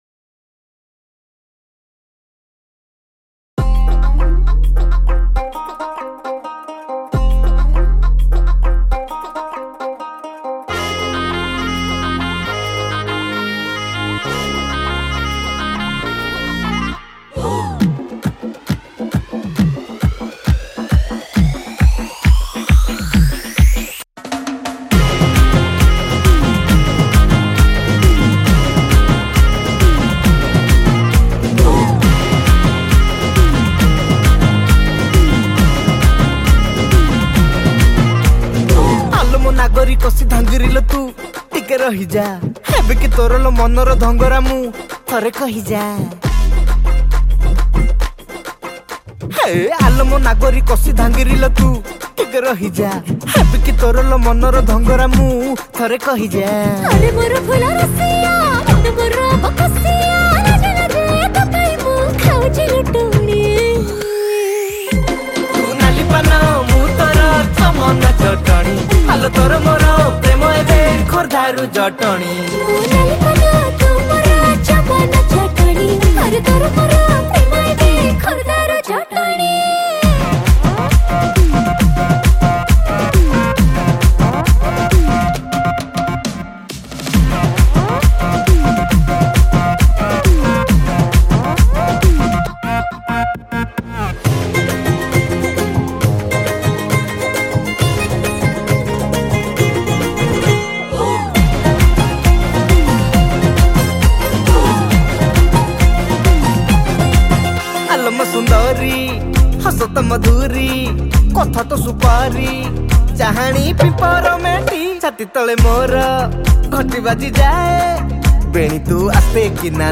Chorus